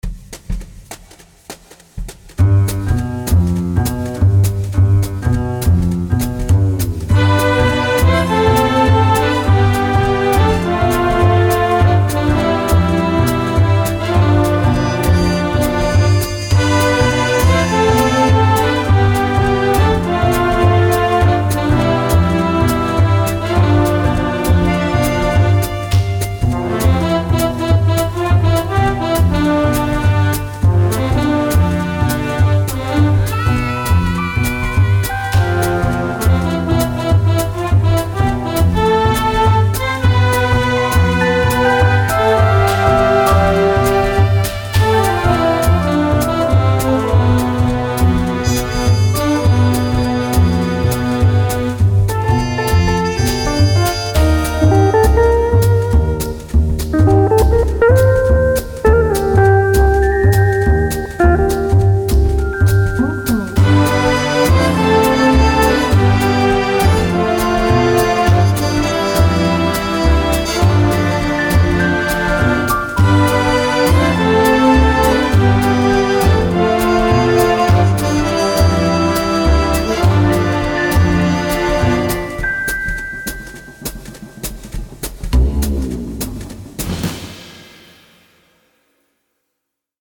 Home > Music > Jazz > Bright > Smooth > Laid Back